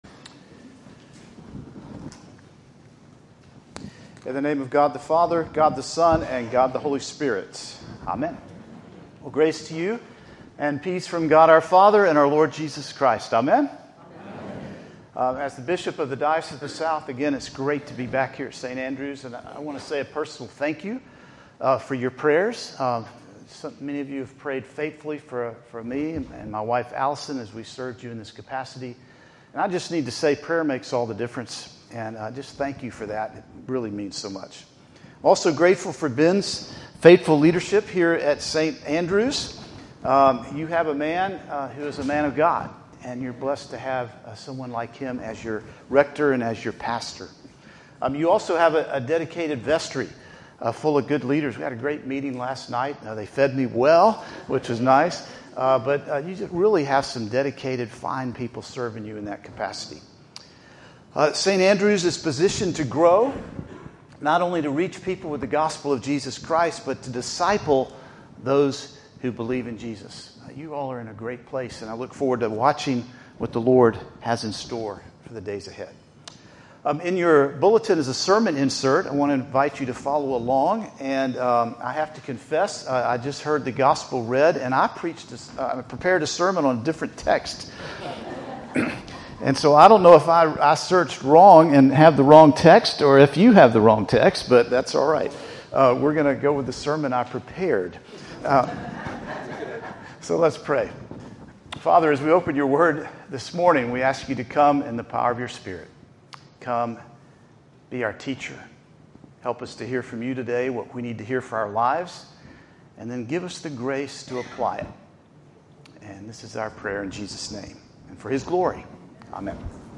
In this special sermon from Archbishop Foley Beach, archbishop emeritus of the ACNA and bishop of the Anglican Diocese of the South, on this feast of St. Bartholomew we hear about what greatness in the Kingdom of God looks like, which is very different than greatness in the world. Greatness in the Kingdom of God is service.